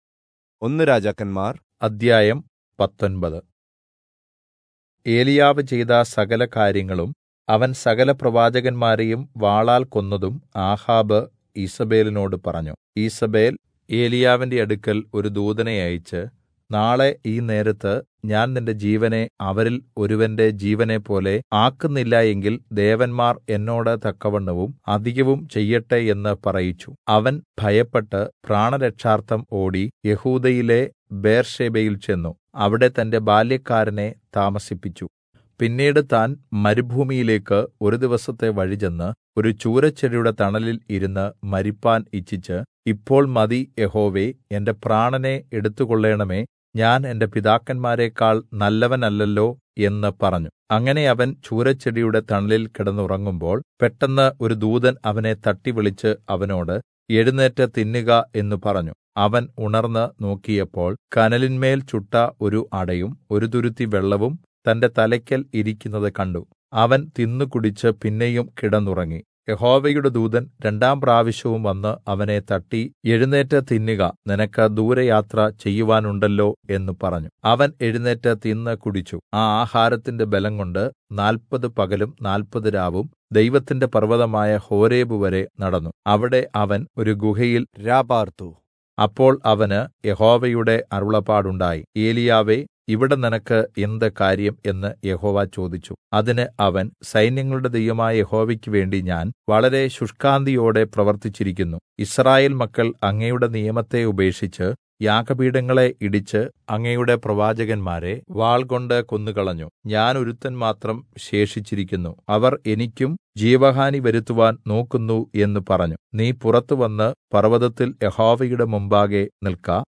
Malayalam Audio Bible - 1-Kings 14 in Irvml bible version